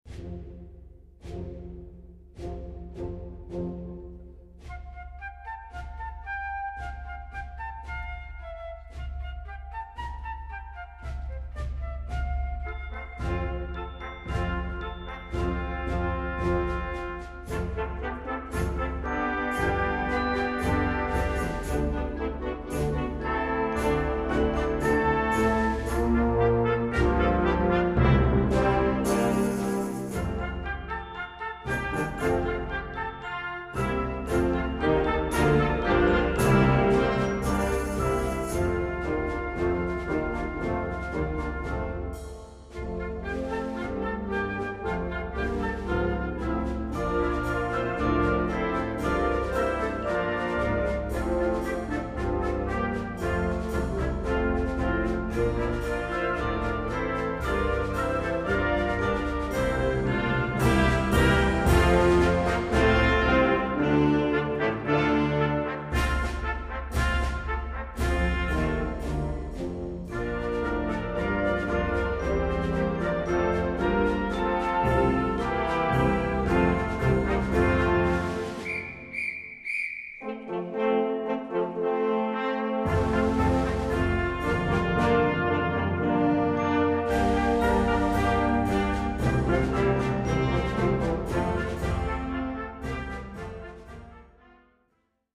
Concert Band ou Harmonie